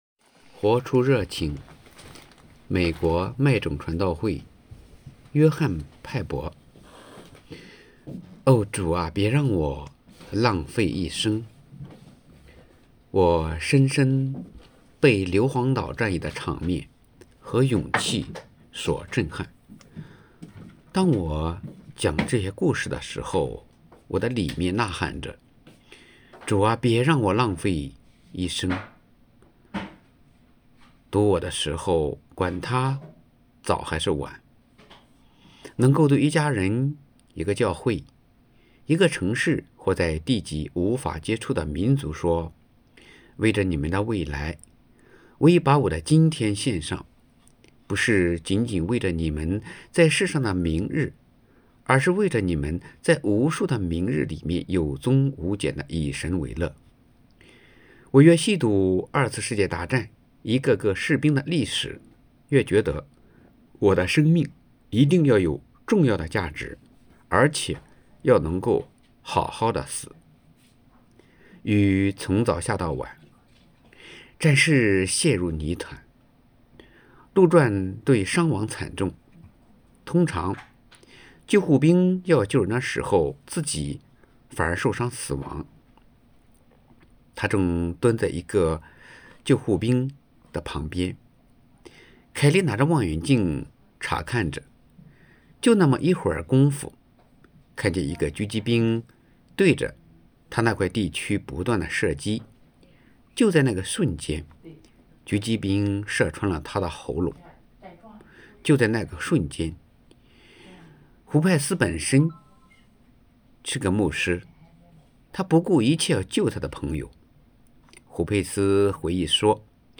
2024年6月12日 “伴你读书”，正在为您朗读：《活出热情》 欢迎点击下方音频聆听朗读内容 音频 https